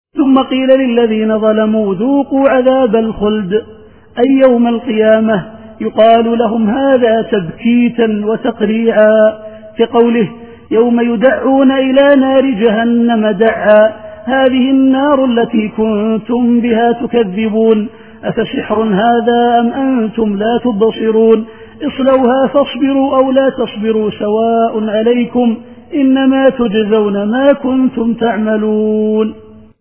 التفسير الصوتي [يونس / 52]